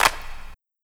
Car Wash Clap2.wav